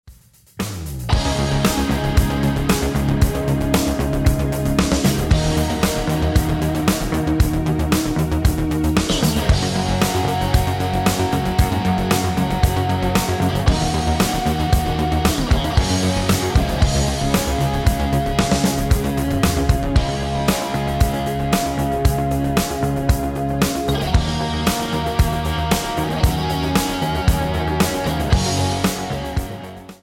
Tonart:Db ohne Chor